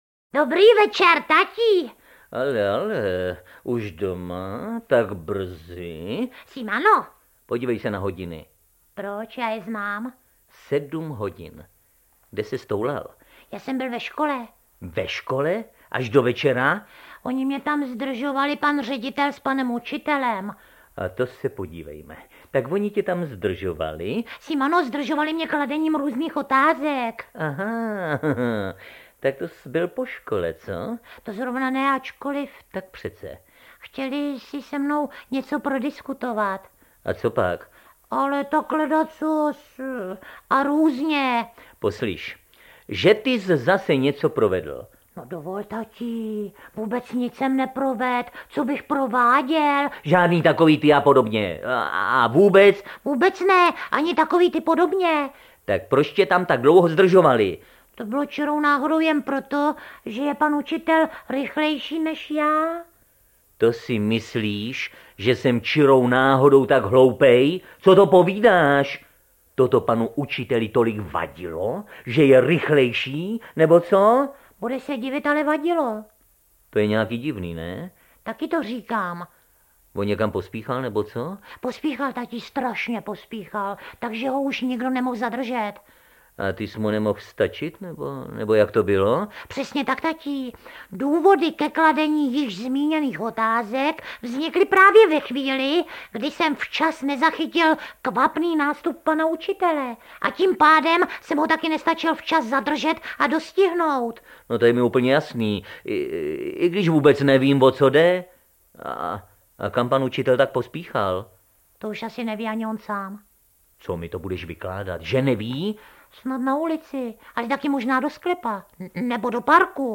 Audio kniha
Ukázka z knihy
• InterpretJosef Somr, Ivan Trojan, Hana Maciuchová, Jiří Klem, Petr Kostka, Petr Nárožný, Tereza Bebarová, Pavel Zedníček, Jaroslav Satoranský, Josef Dvořák, Miloš Kirschner, Bronislav Poloczek, Bohdan Tůma, Ondřej Brzobohatý